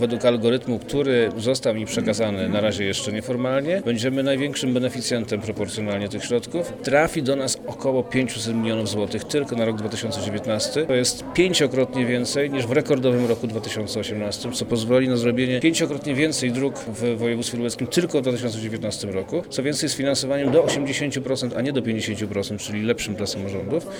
Przed nami byłoby tylko województwo mazowieckie i wielkopolskie – mówi wojewoda lubelski Przemysław Czarnek (na zdj.).